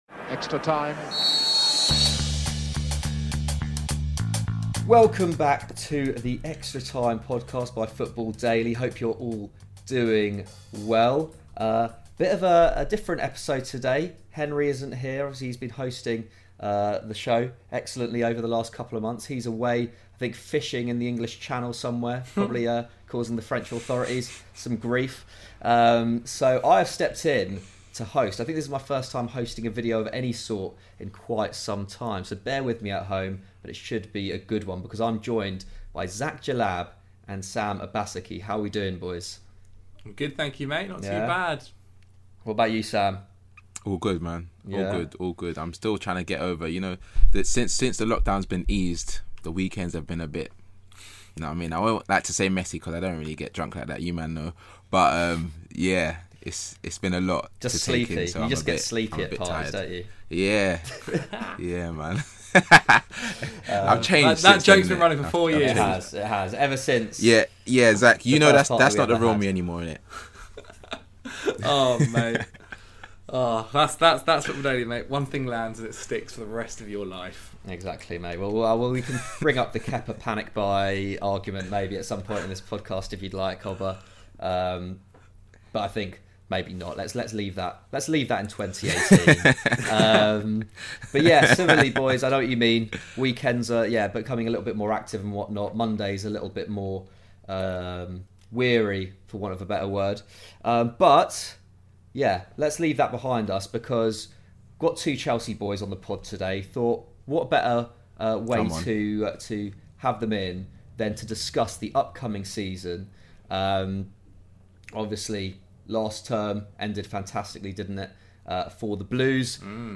Sports News, News, Soccer, Sports